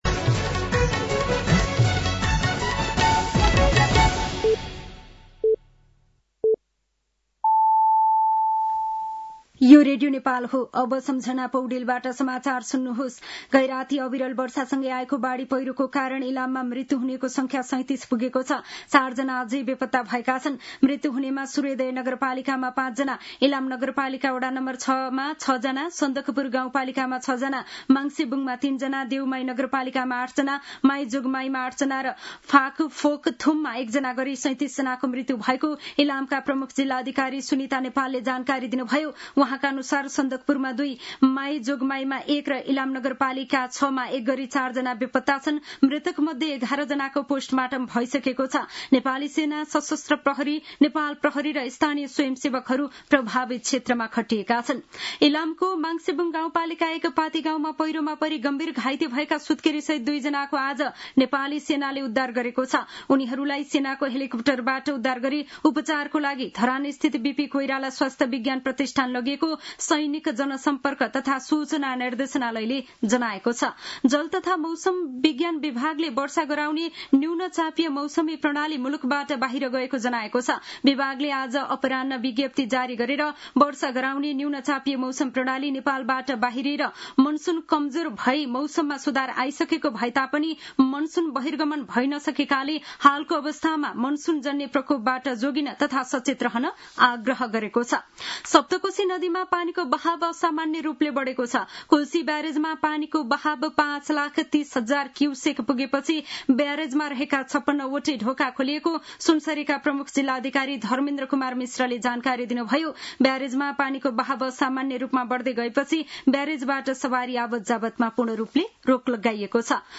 साँझ ५ बजेको नेपाली समाचार : १९ असोज , २०८२
5-pm-news-6-19.mp3